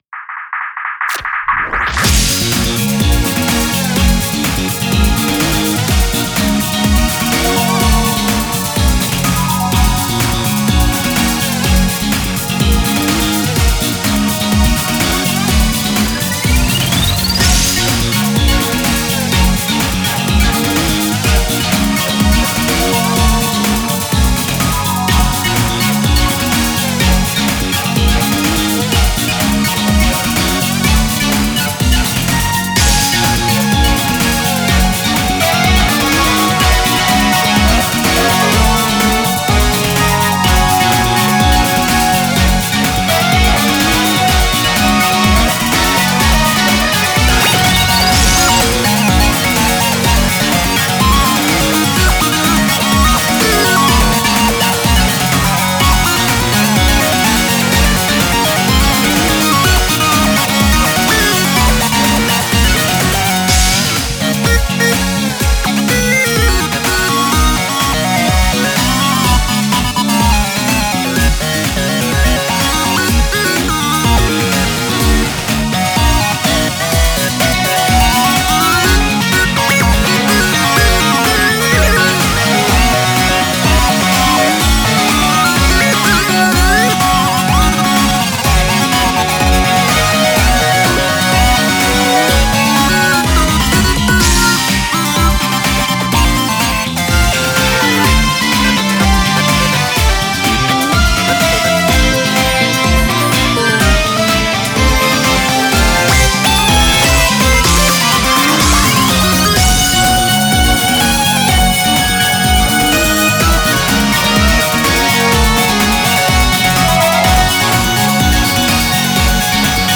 明るい雰囲気の曲です。
アンプ通したブラスの音は便利です
タグ あかるい